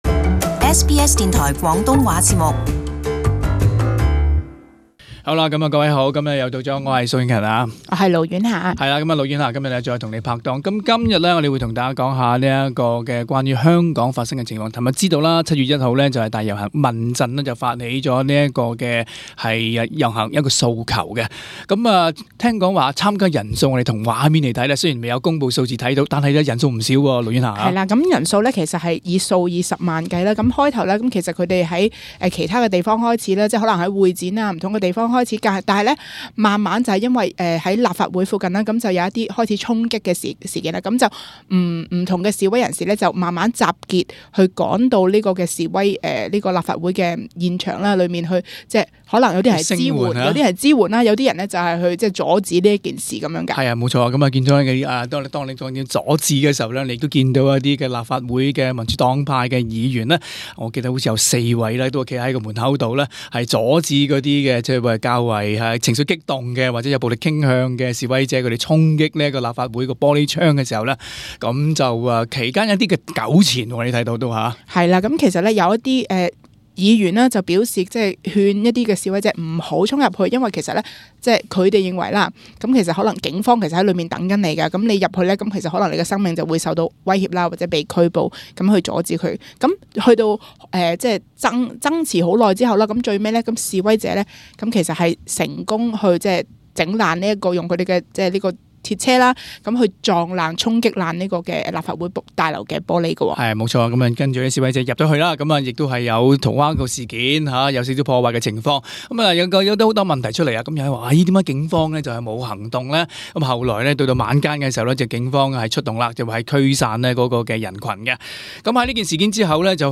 【時事報導】香港特首林鄭強力譴責七一示威者衝擊立法會暴力行為